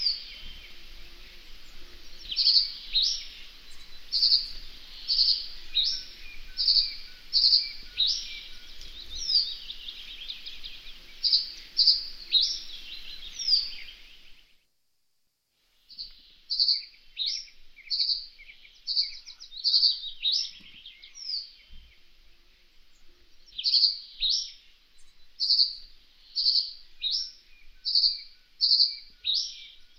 bruant-des-roseaux.mp3